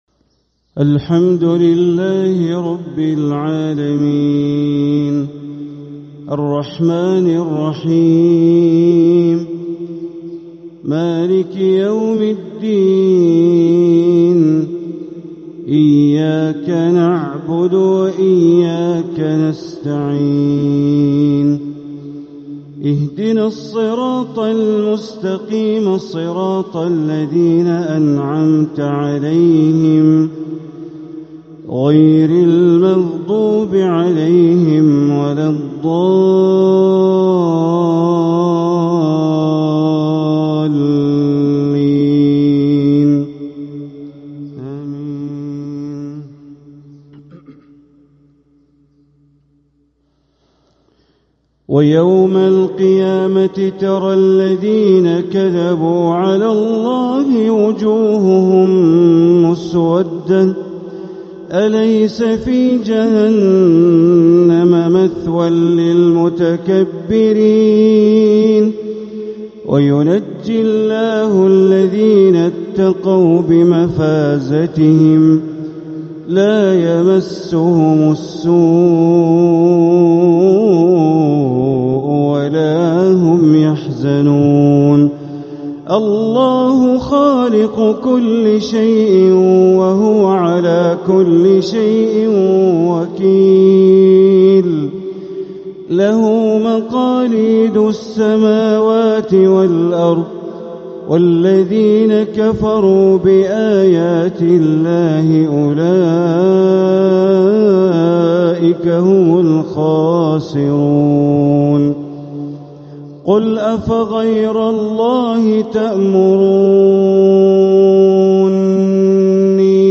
أداء تدبريٍ خاشع لخواتيم سورة الزمر 60-75 للشيخ د. بندر بليلة | فجر السبت 1-6-1447هـ > 1447هـ > الفروض - تلاوات بندر بليلة